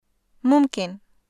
シリアのアラビア語語彙モジュール